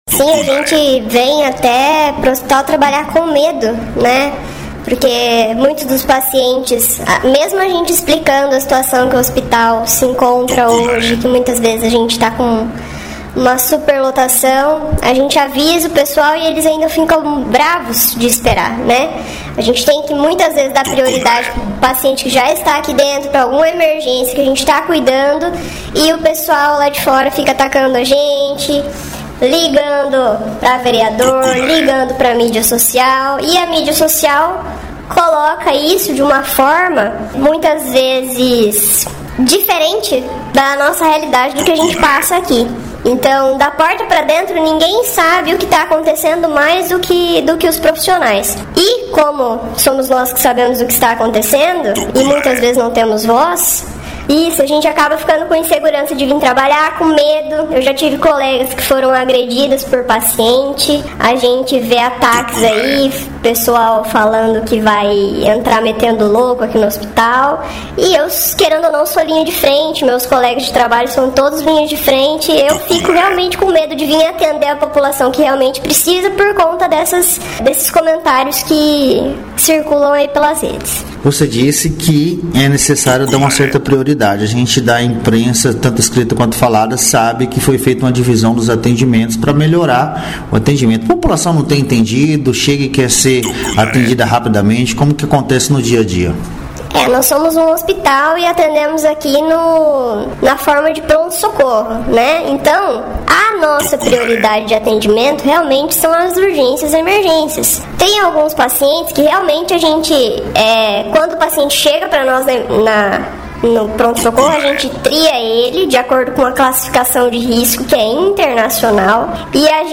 Os áudios com os relatos foram gravados com autorização das servidoras e tiveram suas vozes modificadas para garantir o anonimato, no entanto, o conteúdo completo com a denúncias em áudio modificado estão postadas no final dessa matéria e o conteúdo original, sem alterações, bem como os nomes e identificação ficarão à disposição da justiça, caso seja solicitado.
AUDIOS DAS ENTREVISTAS (COM AS VOZES MODIFICADAS) REALIZADAS NA TARDE DO DIA 28.05.2025 NO HOSP MUNICIPAL DE JUARA NA PRESENÇA DA DIREÇÃO: